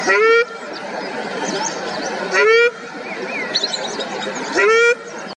赤麻鸭鸣声